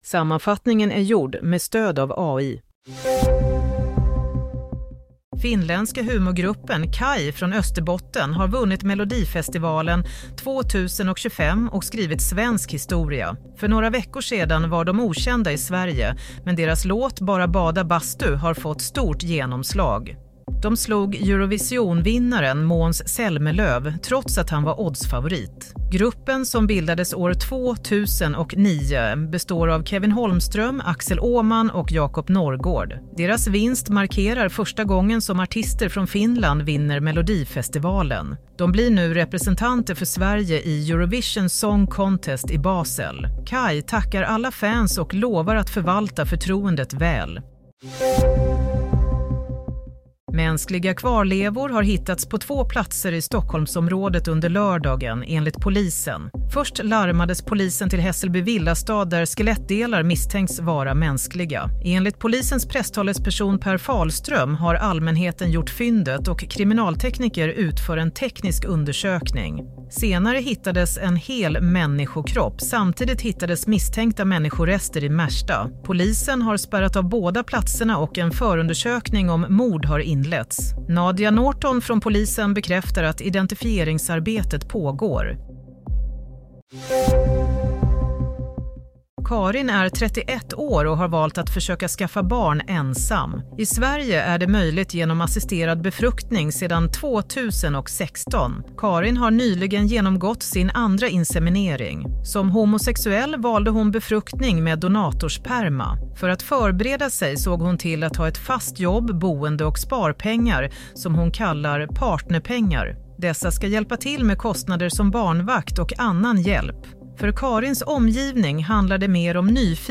Nyhetssammanfattning - 9 mars 07:00
Sammanfattningen av följande nyheter är gjord med stöd av AI.